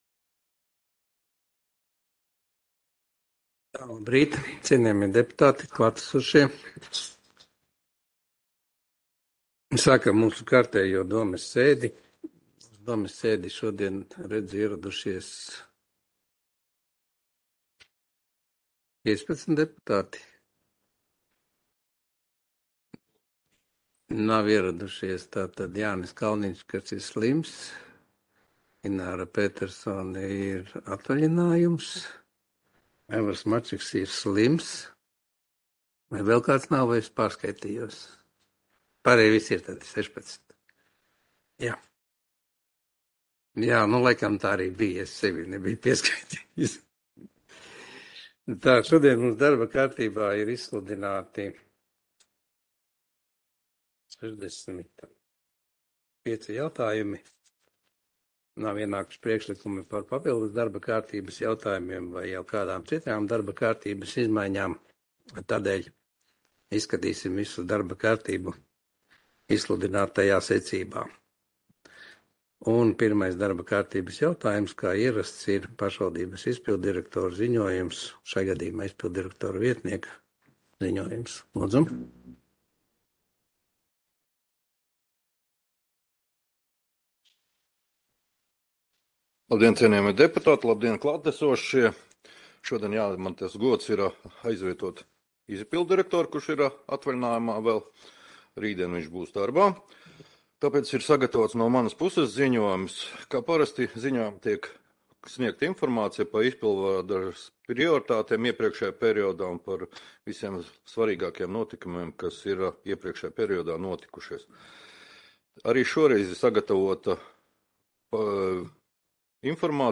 Audioieraksts - 2022.gada 29.septembra domes sēde